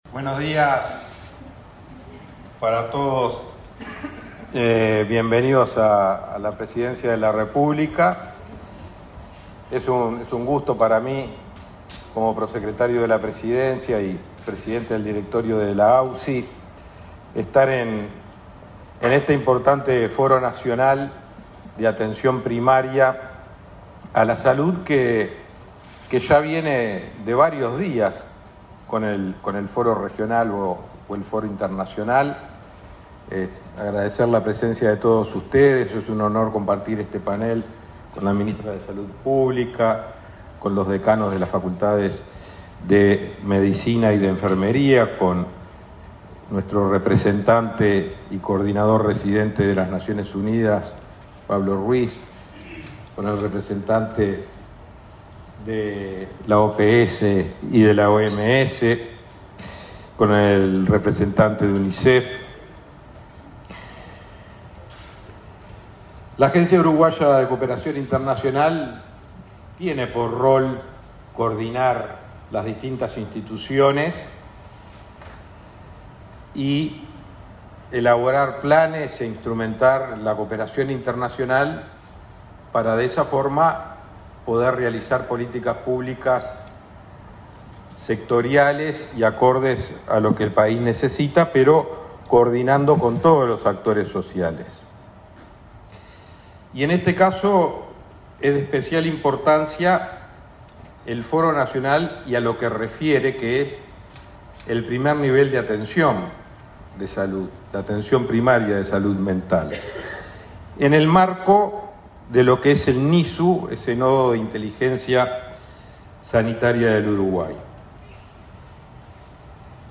Palabra de autoridades en el Foro Nacional de Atención Primaria en Salud
El prosecretario de la Presidencia, Rodrigo Ferrés, y la ministra de Salud Pública, Karina Rando, participaron, este jueves 7 en Torre Ejecutiva, del